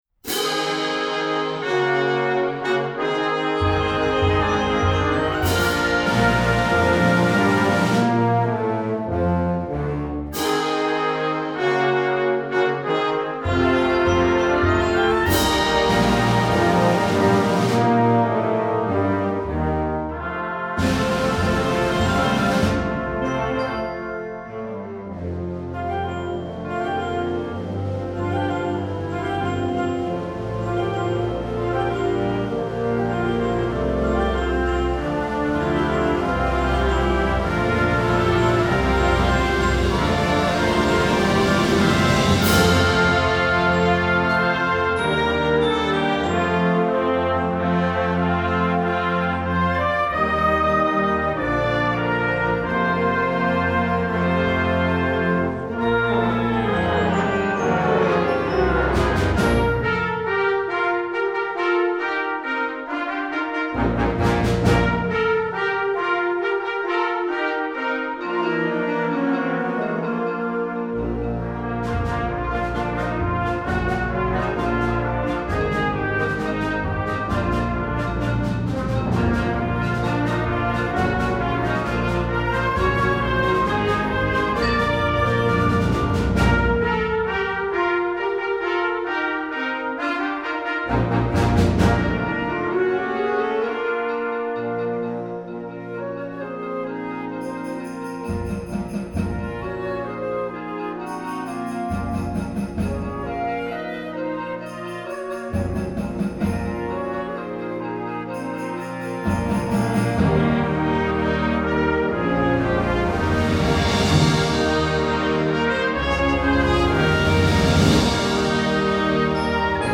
Gattung: Jugendwerk
3.30 Minuten Besetzung: Blasorchester PDF